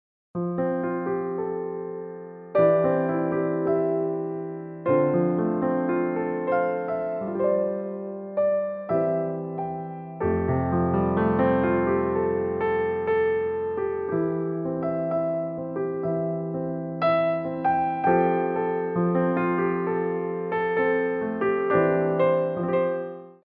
Développés
4/4 (8x8)